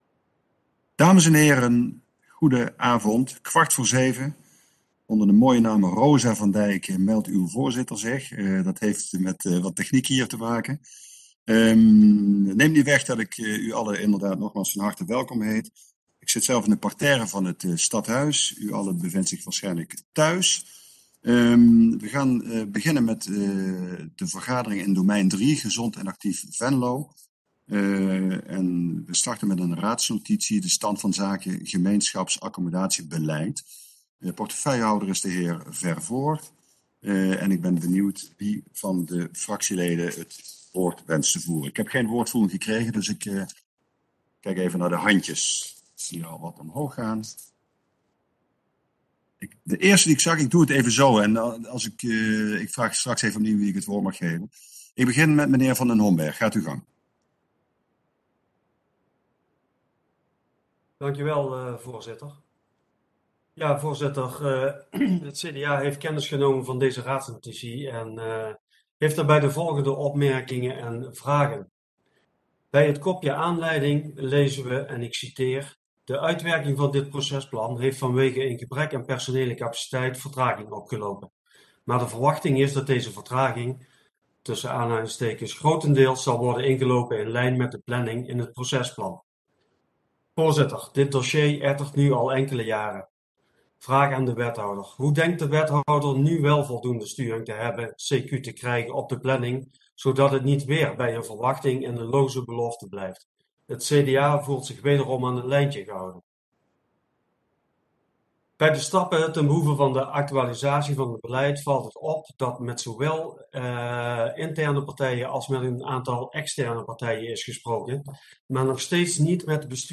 Oordeelsvormende raadsvergadering 08 december 2021 18:00:00, Gemeente Venlo
Stadhuis Raadzaal